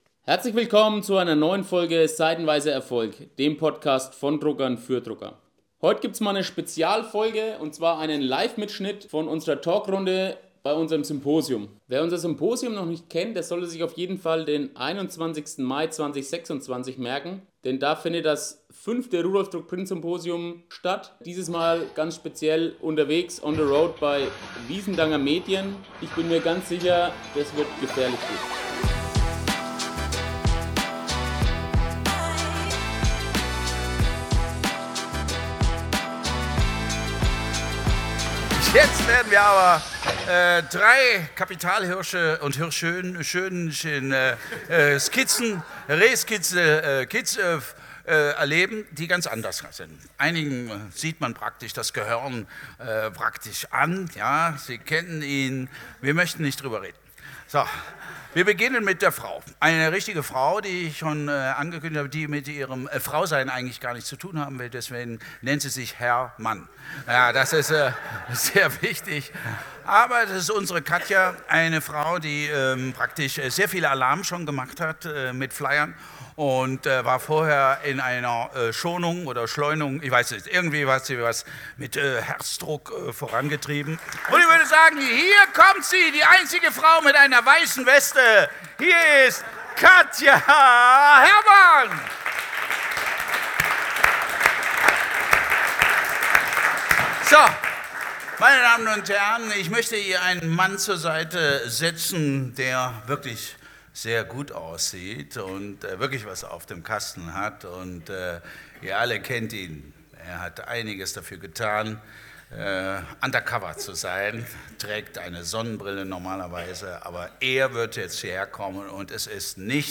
In dieser Folge von „Seitenweise Erfolg“ freue ich mich über einen weiteren live Mitschnitt von unserem Symposium!
Offen, ehrlich und mit viel Humor gehen wir den ganz praktischen Herausforderungen und Chancen der Druckbranche auf den Grund. Es wird lebendig, und jeder von uns bringt seine Erlebnisse und Perspektiven ein.